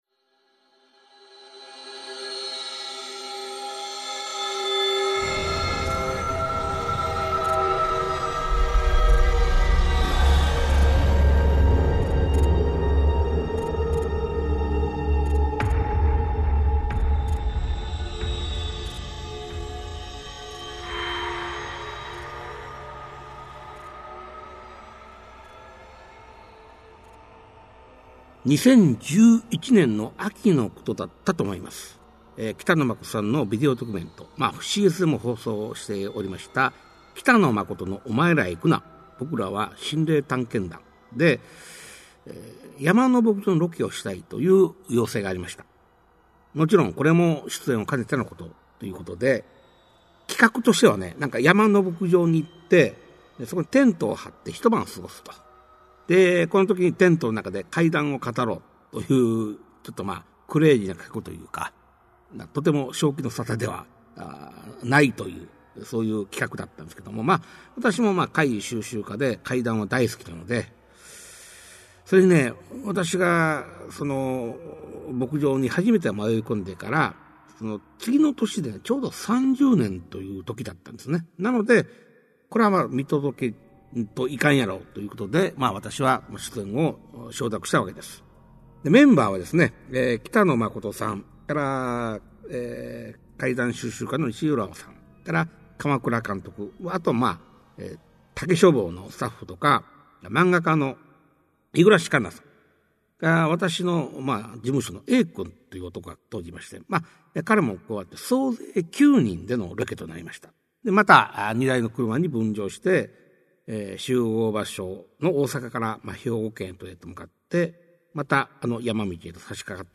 [オーディオブック] 市朗怪全集 五十二